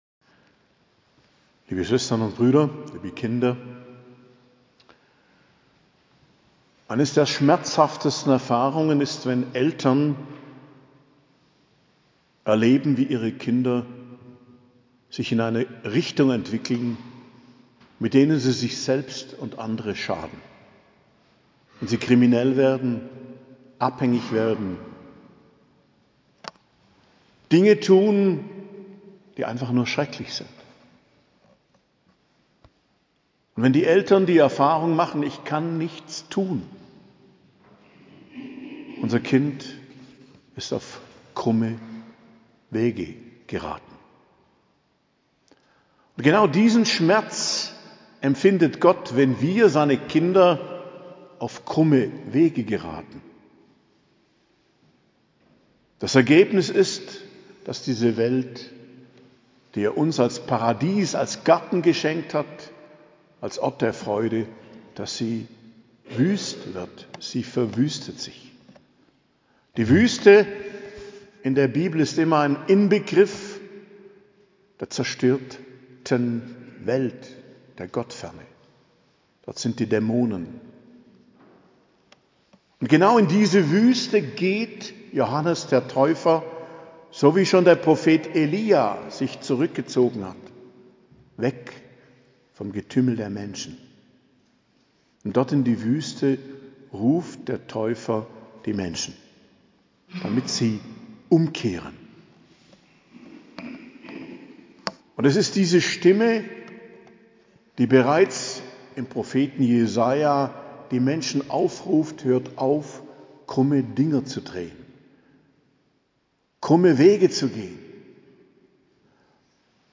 Predigt zum 2. Adventssonntag, 10.12.2023